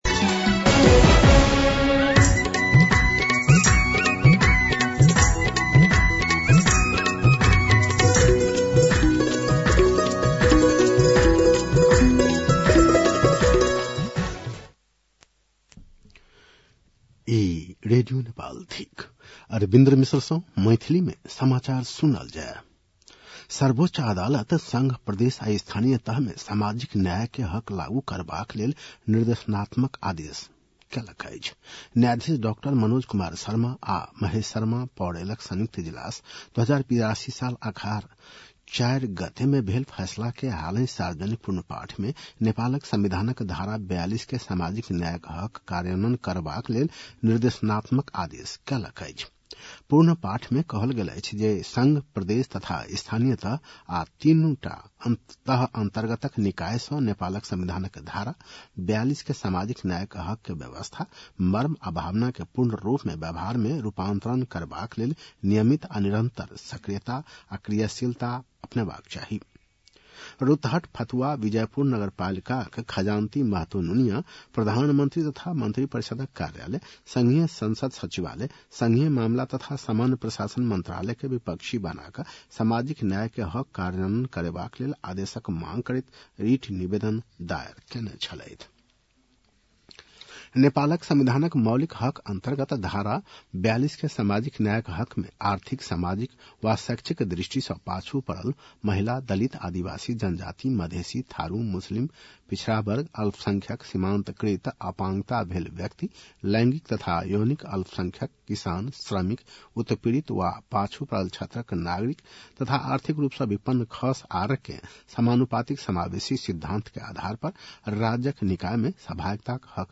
मैथिली भाषामा समाचार : १८ माघ , २०८२